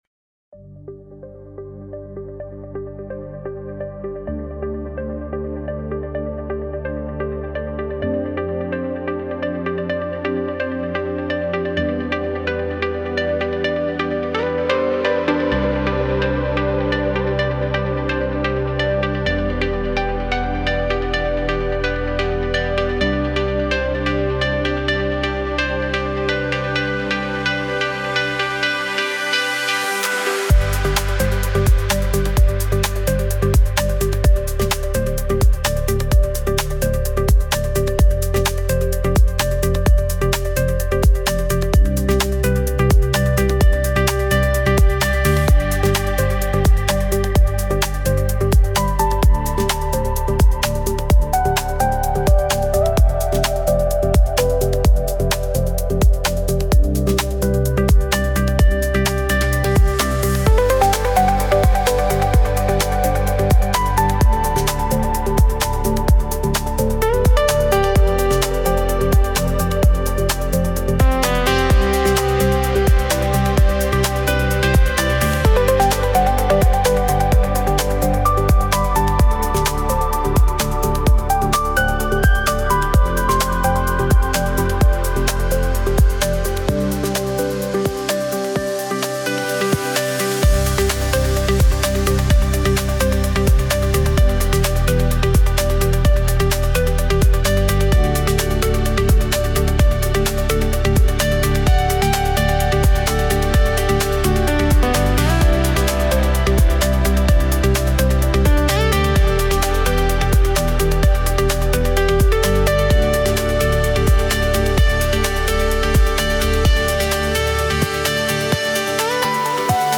Genre Progressive House